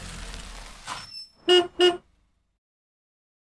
Bolt stop sound.mp3